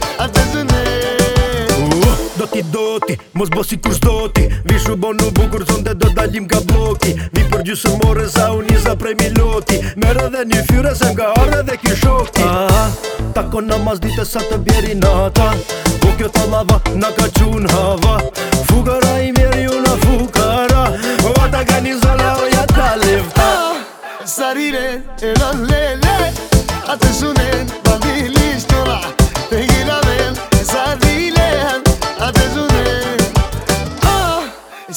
Жанр: Латиноамериканская музыка